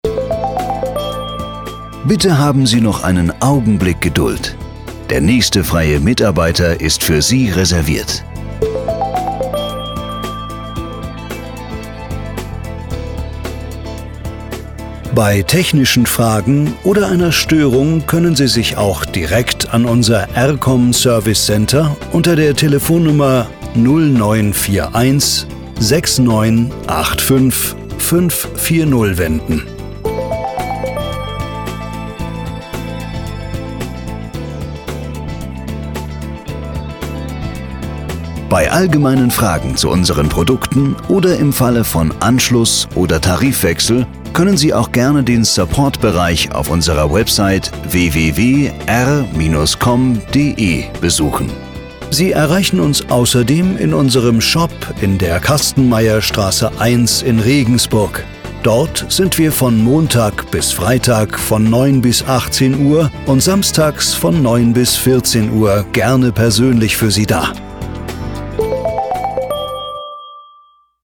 Warteschleife
R-KOM-Warteschleife-08-22.mp3